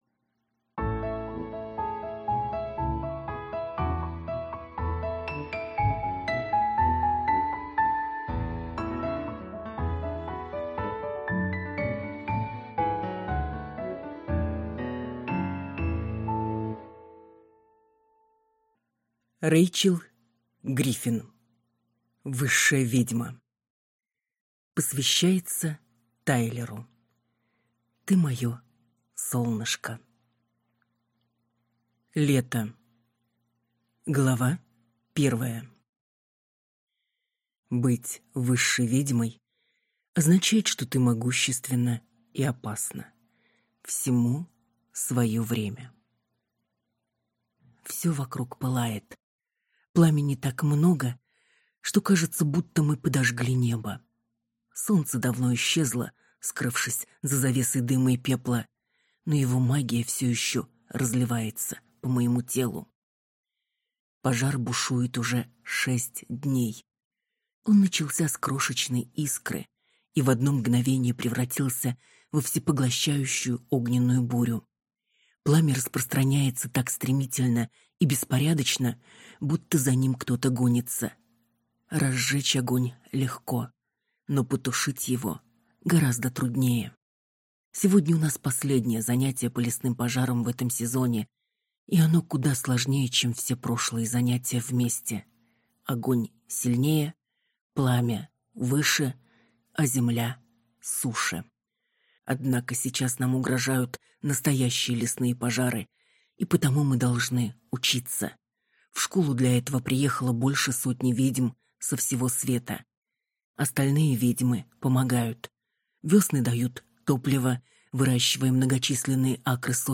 Аудиокнига Высшая ведьма | Библиотека аудиокниг